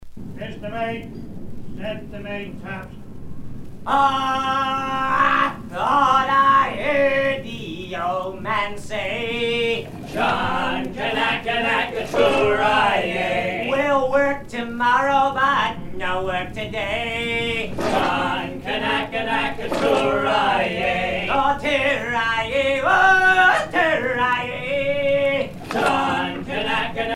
gestuel : à hisser à grands coups
circonstance : maritimes
Pièce musicale éditée